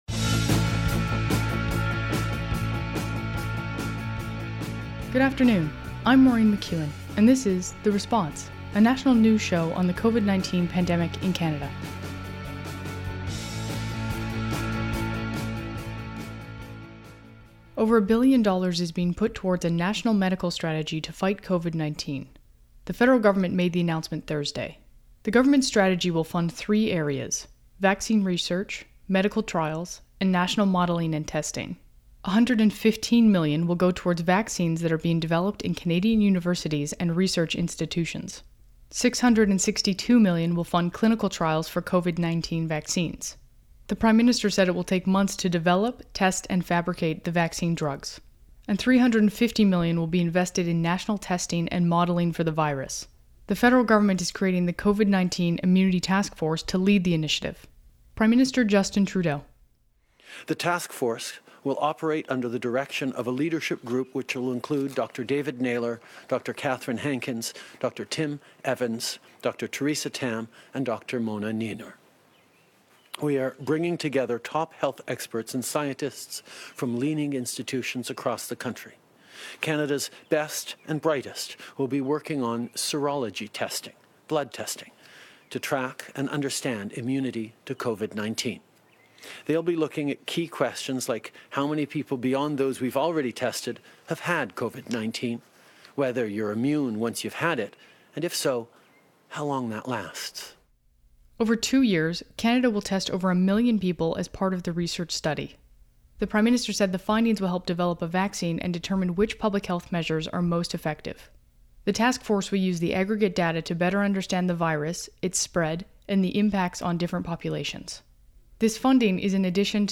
National News Show on COVID-19
Credits: Audio clips: Canadian Public Affairs Channel.
Type: News Reports
192kbps Stereo